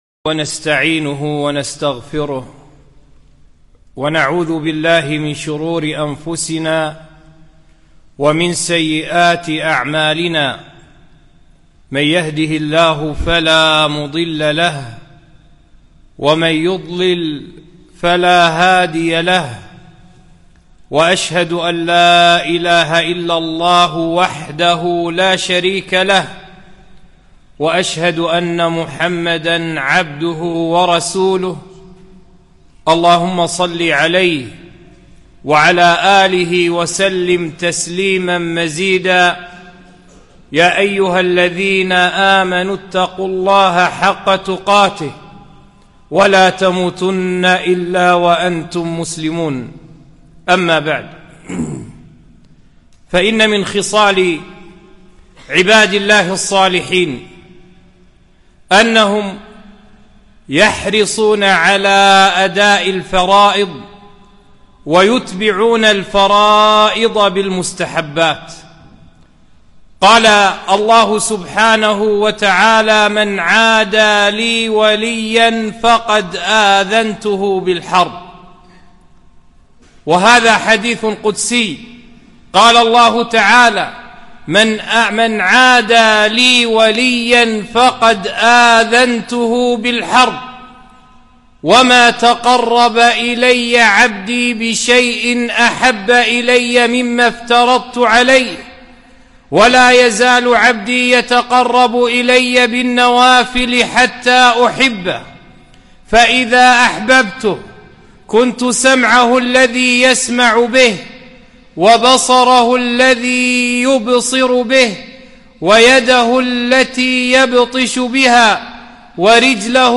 خطبة - إن الله قد فرض الحج